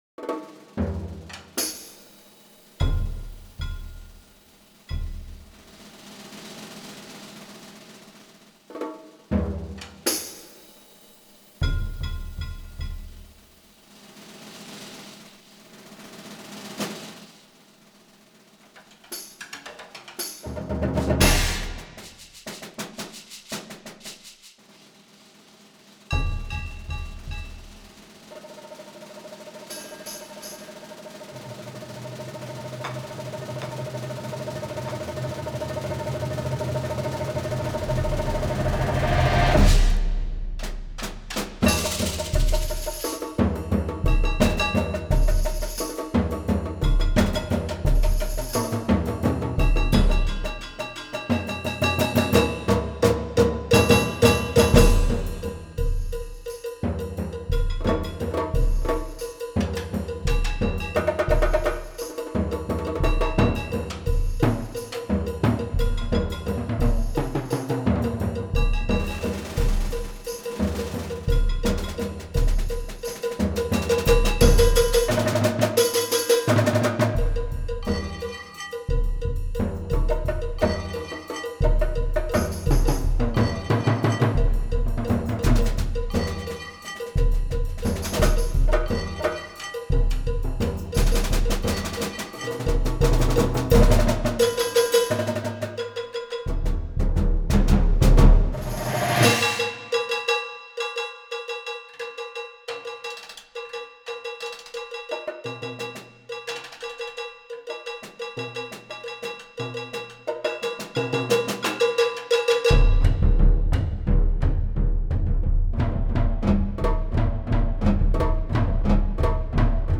Voicing: Percussion Nonet